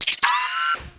command_off.ogg